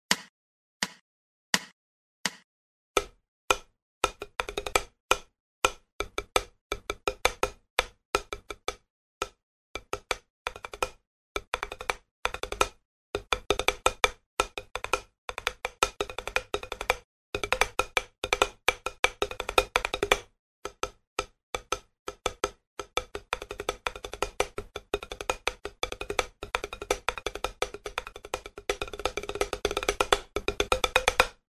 Все этюды записаны на педе для большей разборчивости и возможно помогут тем кто занимается по указанной книге самостоятельно.
Этюд №6 построен на рудиментальной модели Five Stroke Roll.
Размер 2/2, темп 84
Сыгран без повторов.